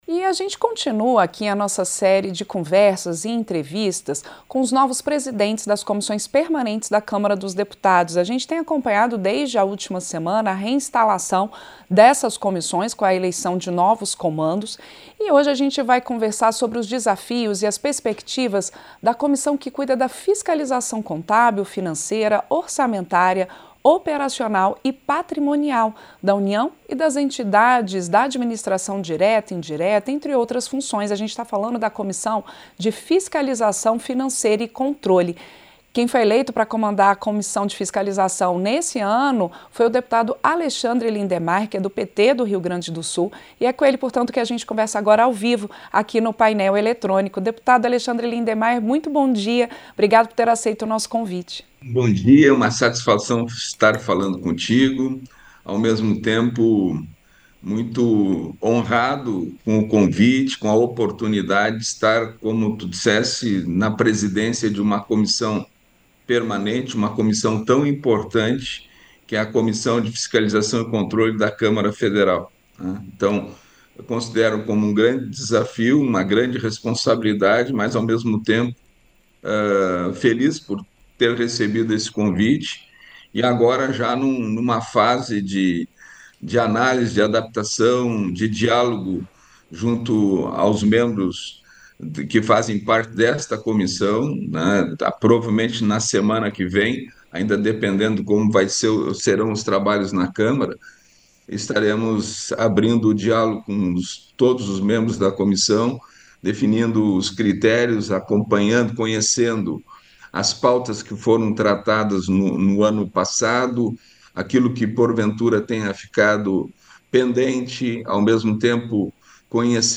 Entrevista - Dep. Alexandre Lindenmeyer (PT-RS)